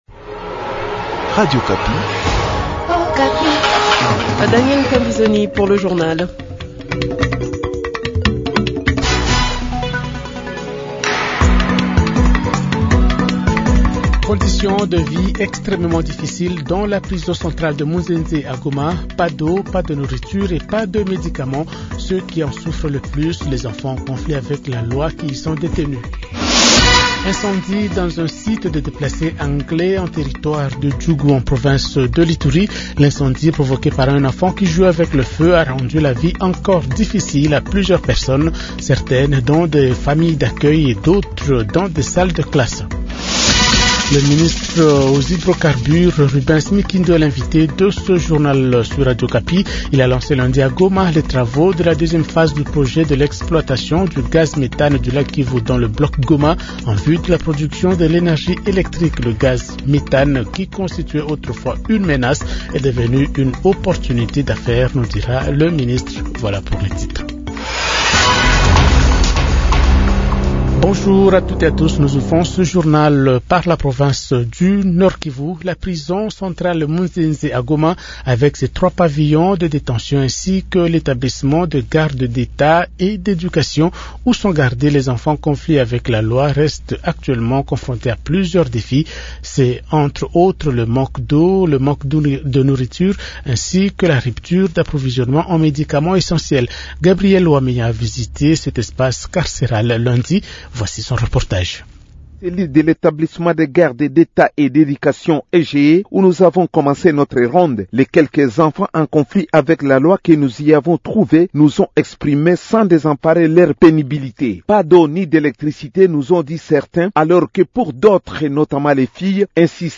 Journal Francais Matin 8h00